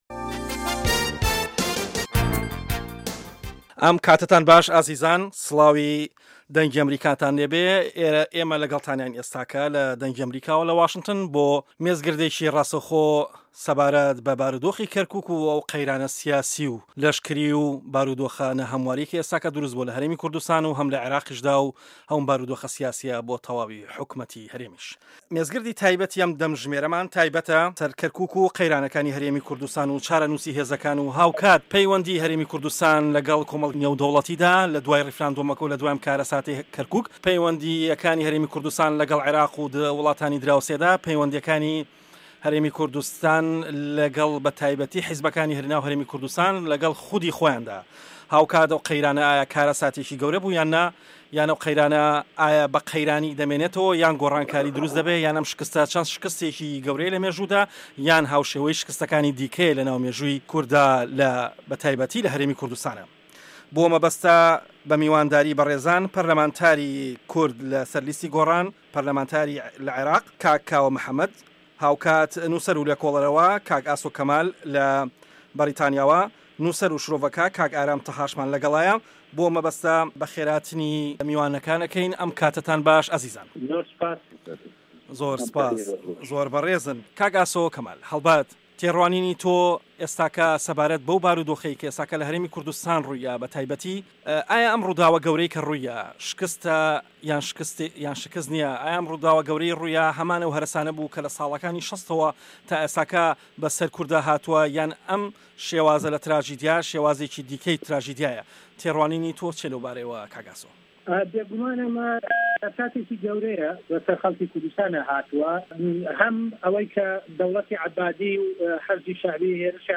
مێزگرد: هەرێمی کوردستان لەبەردەم دەستور و یاساو تراژیدیادا